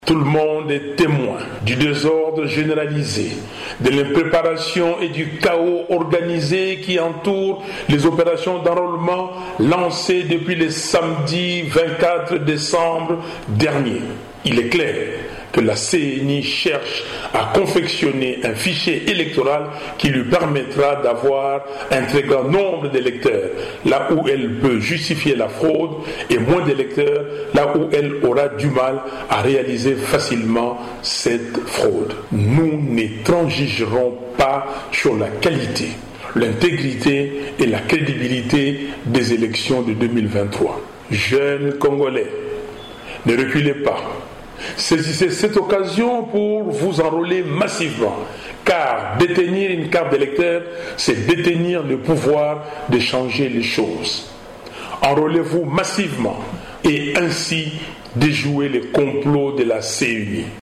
Il a lancé cet appel lors de sa communication à la Nation à Kinshasa :